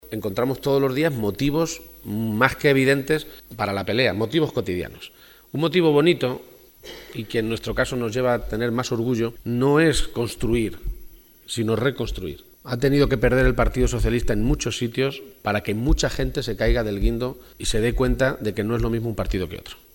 Momento de la intervención de García-Page